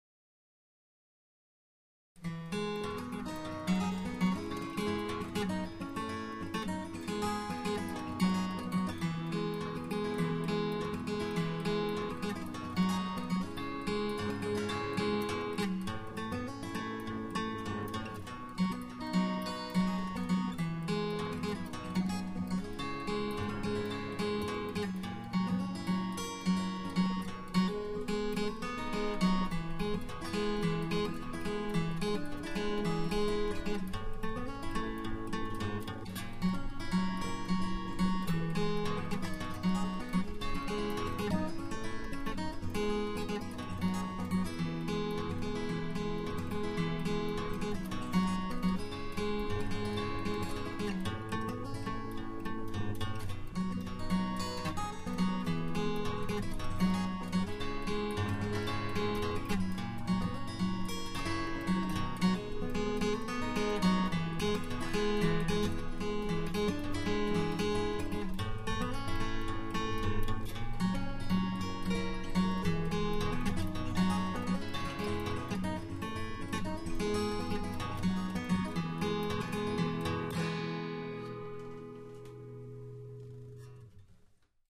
Among these was this little gem of guitar picking